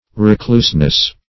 Recluseness \Re*cluse"ness\, n.